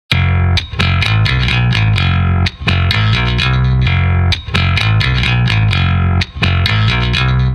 SLK_electric_bass_corn_on_the_cobb_4bar_128_F
bass-corn.mp3